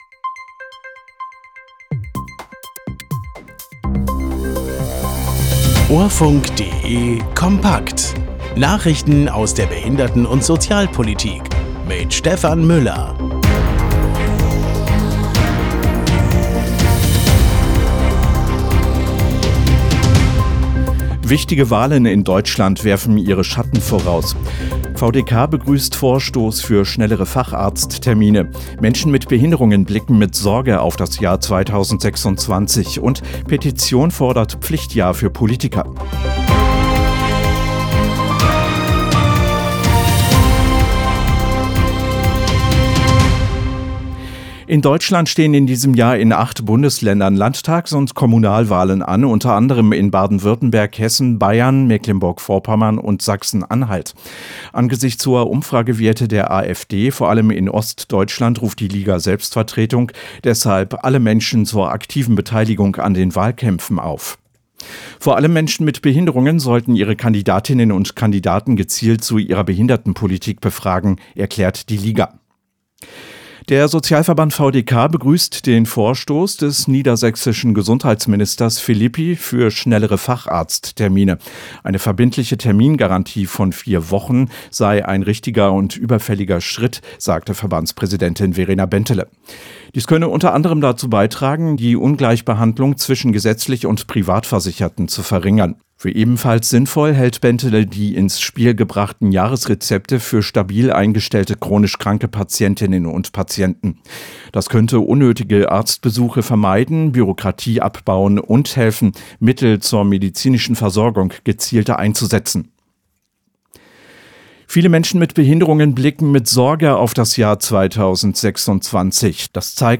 Nachrichten aus der Behinderten- und Sozialpolitik vom 14.01.2026